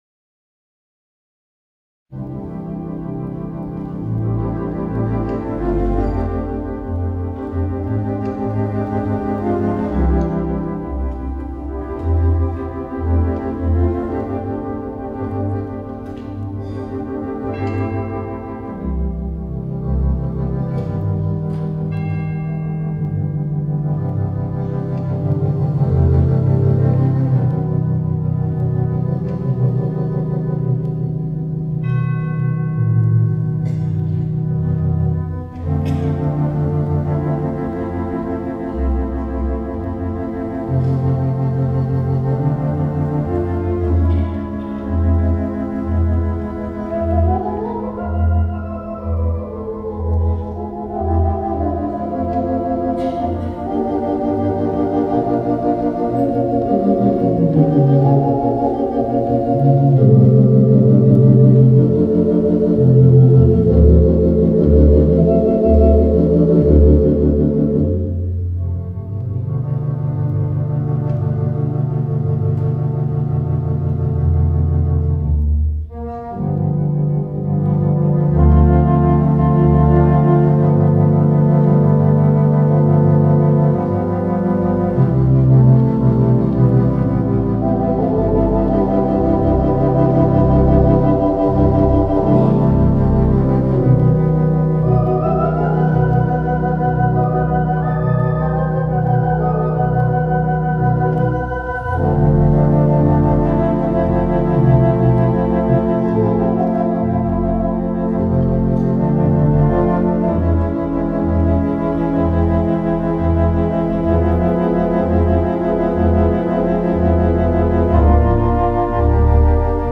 Mighty 2/5 Wicks Theatre Pipe Organ
and in front of an audience of over 200 attendees